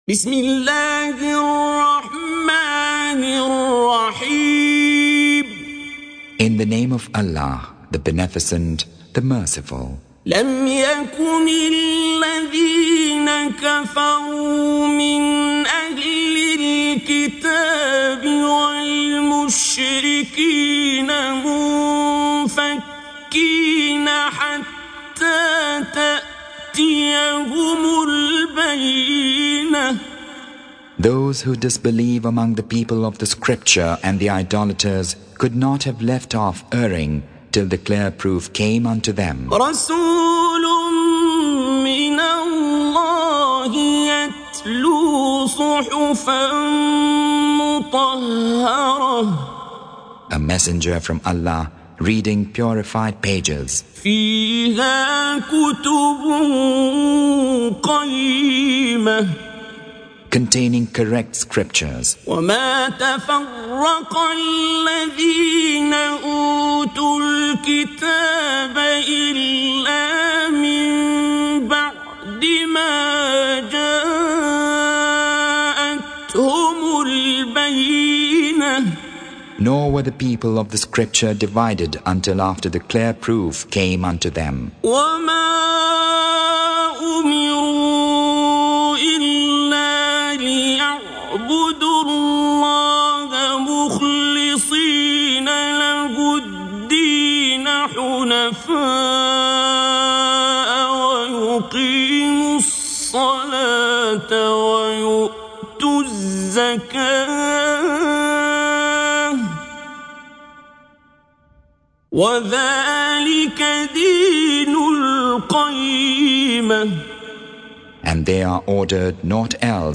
Surah Sequence تتابع السورة Download Surah حمّل السورة Reciting Mutarjamah Translation Audio for 98. Surah Al-Baiyinah سورة البينة N.B *Surah Includes Al-Basmalah Reciters Sequents تتابع التلاوات Reciters Repeats تكرار التلاوات